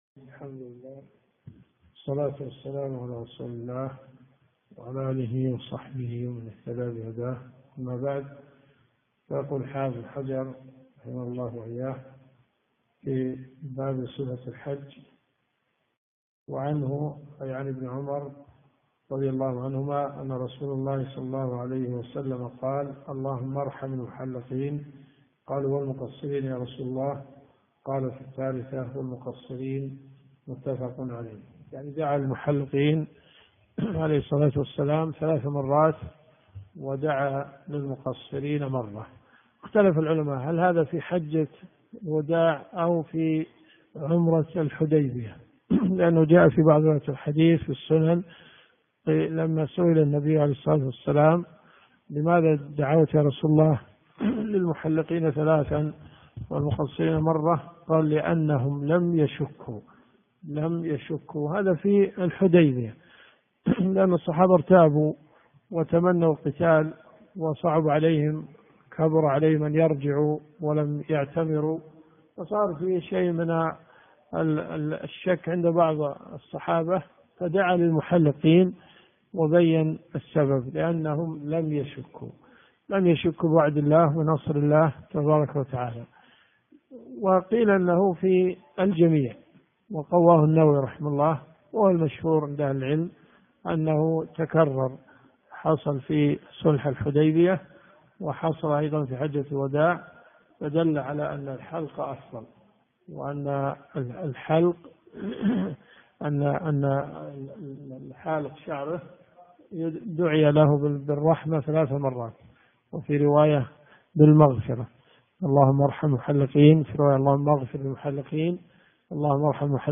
الرئيسية الكتب المسموعة [ قسم أحاديث في الفقه ] > بلوغ المرام .